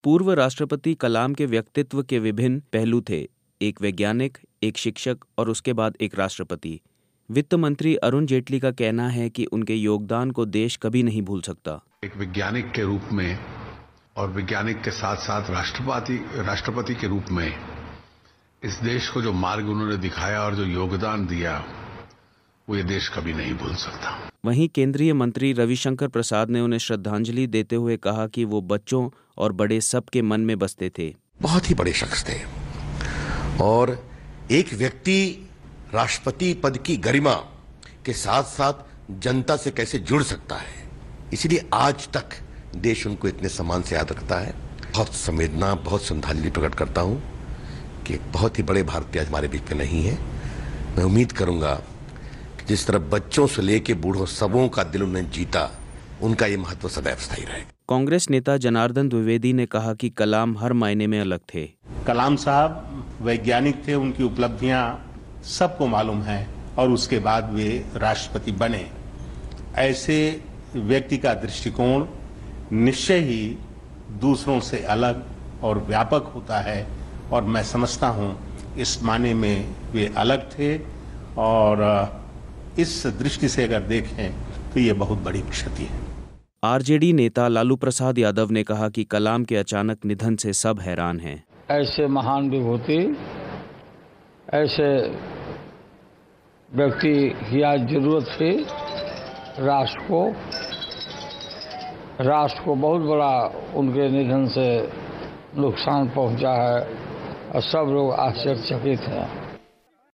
पूर्व राष्ट्रपति अब्दुल कलाम की मृत्यु पर क्या कहा राजनेताओं ने, सुनिए.